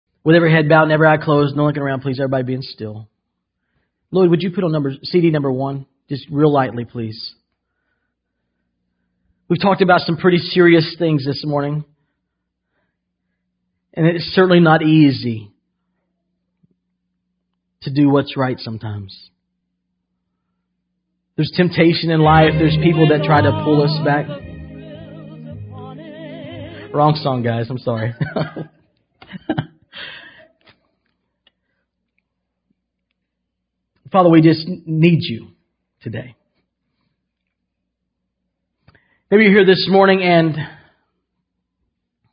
You guessed it, heads are bowed, eyes are closed and Judy Garland starts to sing about 5th avenue.  That is the first time I have ever laughed in an altar call.